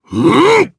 Phillop-Vox_Attack2_jp.wav